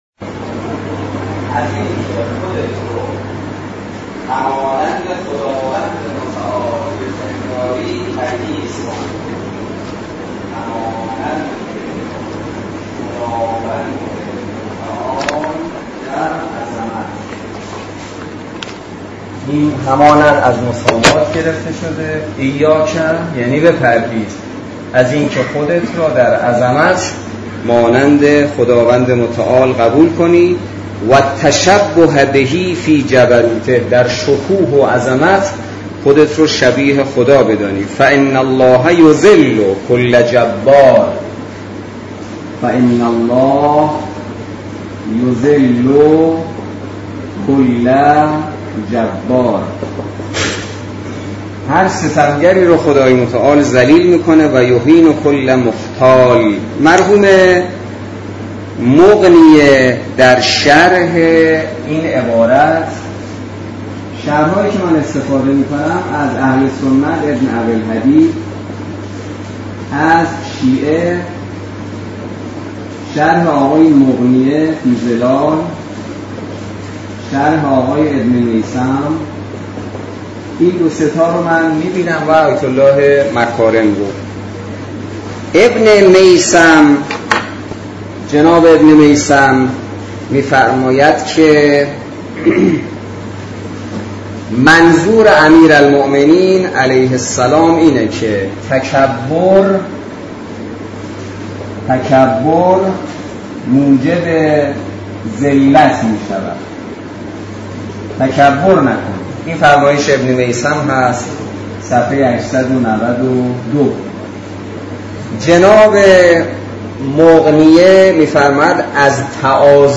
سیزدهمین جلسه مباحثه نامه ۵۳ نهج البلاغه توسط حجت‌الاسلام والمسلمین حسینی نماینده محترم ولی فقیه و امام جمعه کاشان در دانشگاه کاشان برگزار گردید.
سخنرانی امام جمعه کاشان